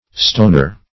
Stoner \Ston"er\, n.